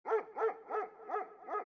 dog4.mp3